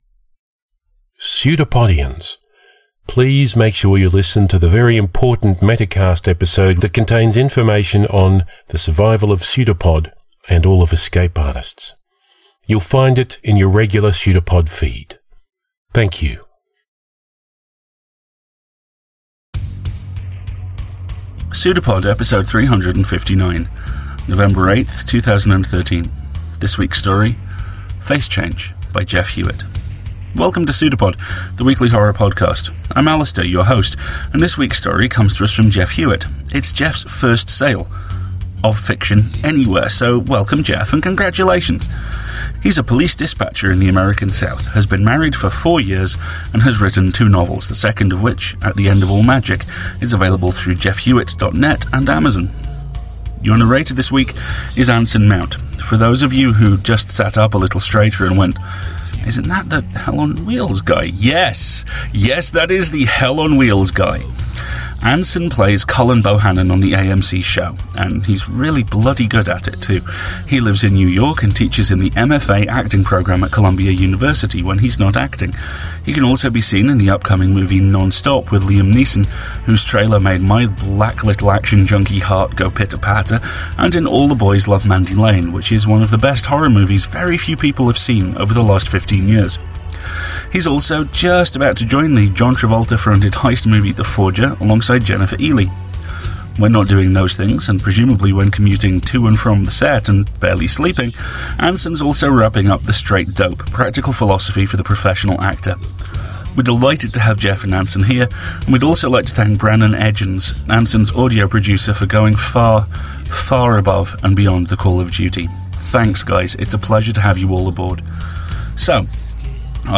Anson reads horror
I was digging around the Internets today and found this podcast of Anson Mount reading